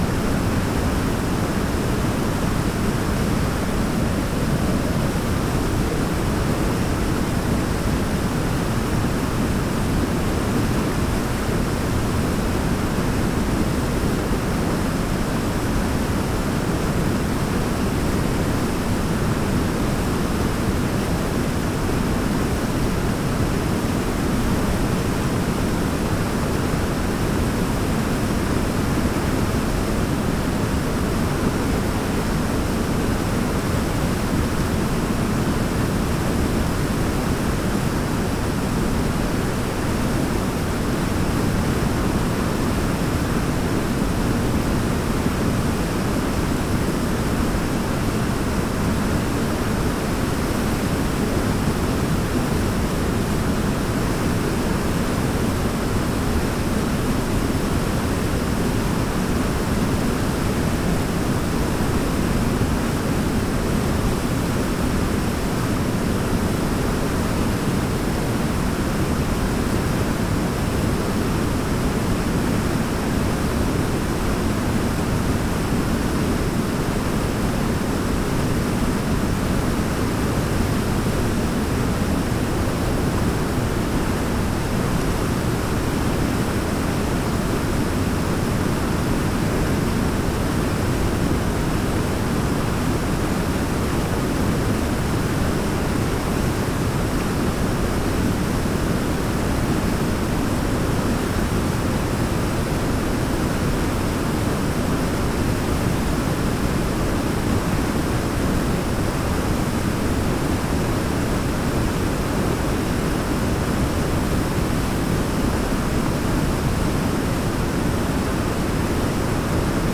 mandrake foundry13data/Data/modules/soundfxlibrary/Nature/Loops/Waterfall
waterfall-1.mp3